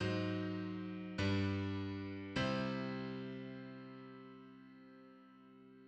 Predominant_German_sixth.mid.mp3